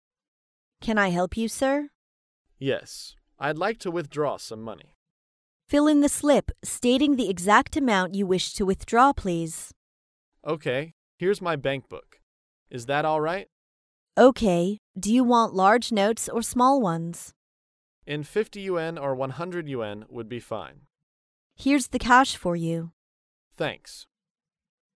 在线英语听力室高频英语口语对话 第425期:取款(3)的听力文件下载,《高频英语口语对话》栏目包含了日常生活中经常使用的英语情景对话，是学习英语口语，能够帮助英语爱好者在听英语对话的过程中，积累英语口语习语知识，提高英语听说水平，并通过栏目中的中英文字幕和音频MP3文件，提高英语语感。